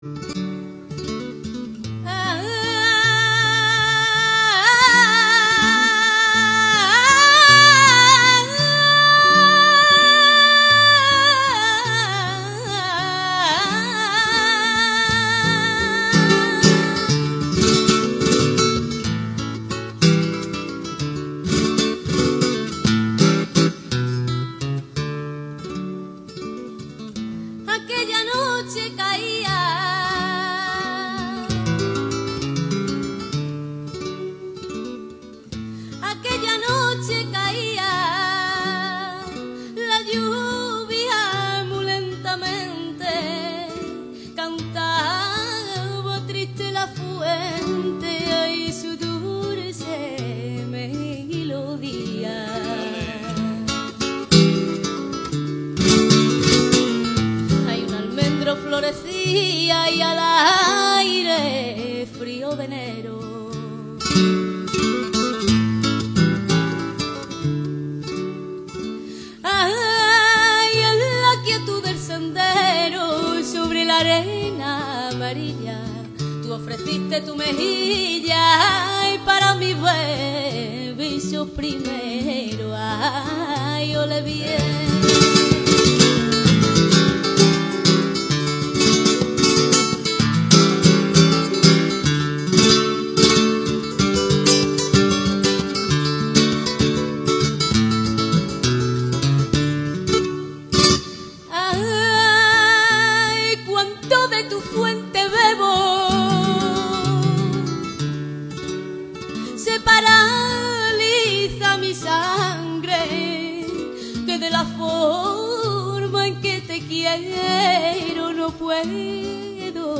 Flamenco en estado puro
Pero antes de eso, los premiados demostraron ante un aforo completo por qué han llegado a ser los ganadores en esta ocasión.
por soleares y granaínas, acompañada a la guitarra